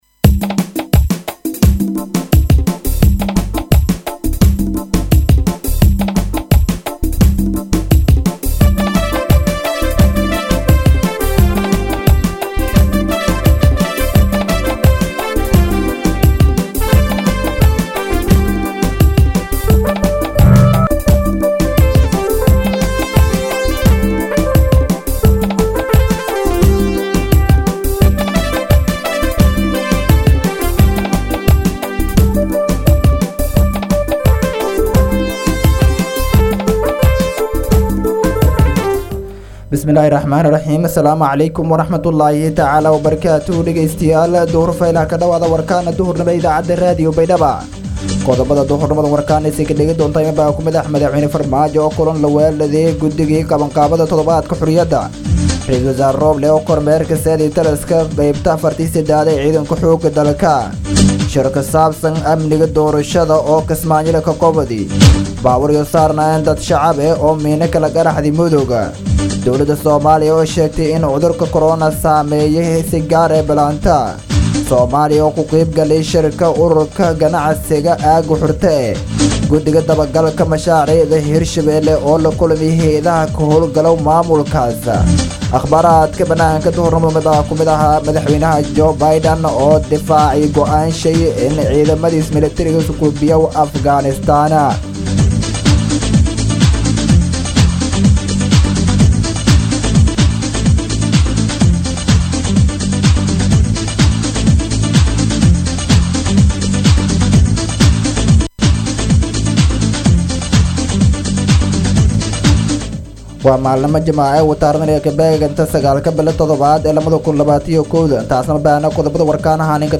DHAGEYSO:- Warka Duhurnimo Radio Baidoa 9-7-2021
BAYDHABO–BMC:–Dhageystayaasha Radio Baidoa ee ku xiran Website-ka Idaacada Waxaan halkaan ugu soo gudbineynaa Warka ka baxay Radio Baidoa.